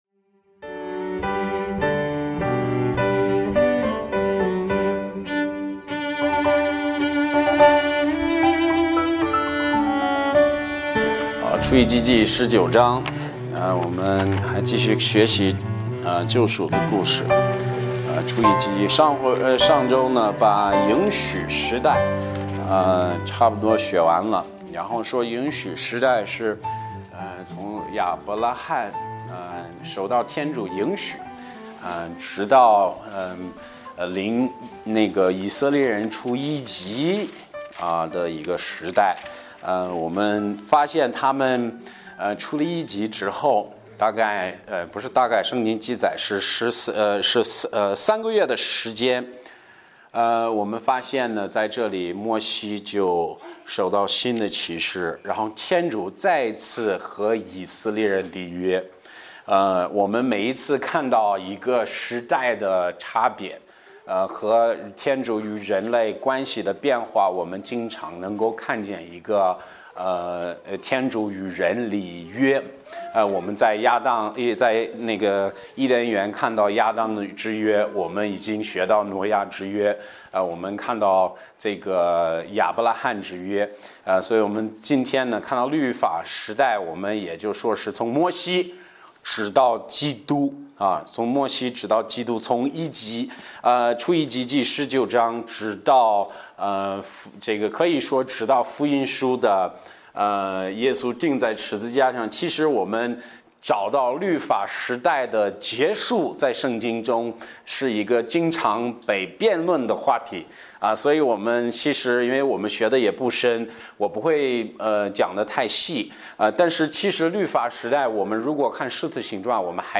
牧师